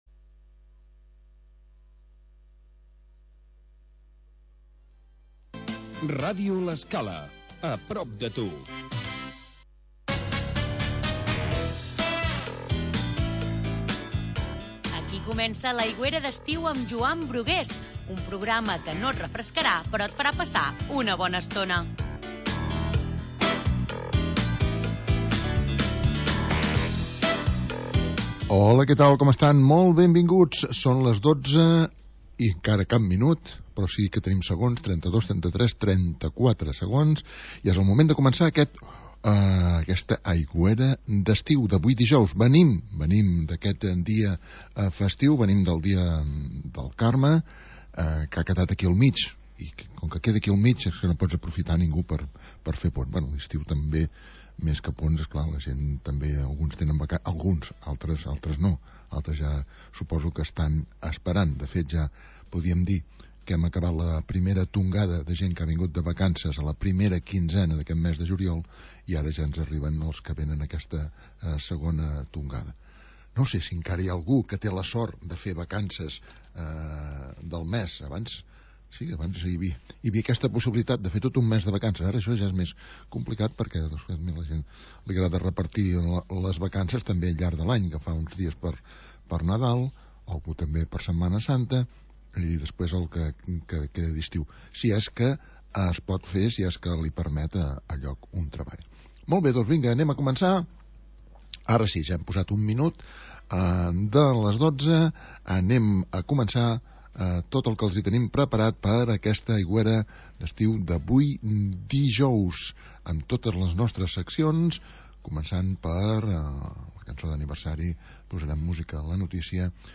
Magazín musical